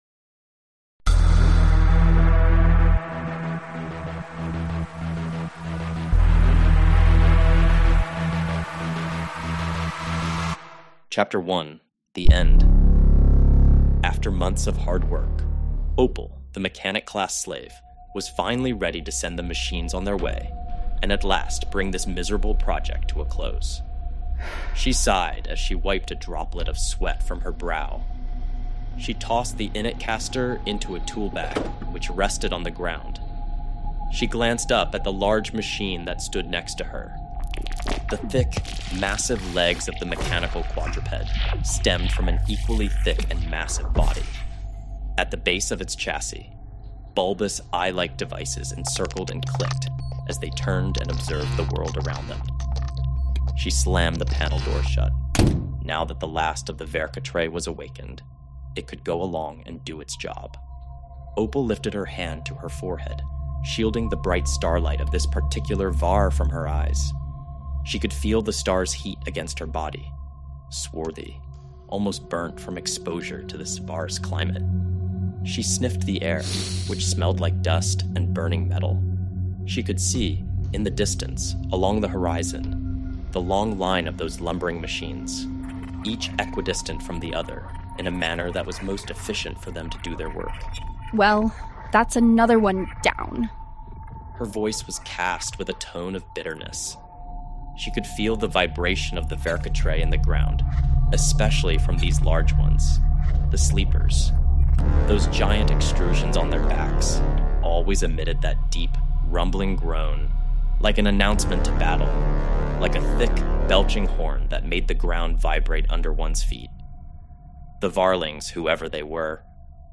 Chapter One of the Slipshot audiobook is now available as a free early listen—a doorway into the moment Frederick’s world begins to shift in ways no one can explain. This preview showcases a full-cast performance, complete with music and sound effects that bring Var 8 to life and hint at the forces quietly gathering beyond its edges.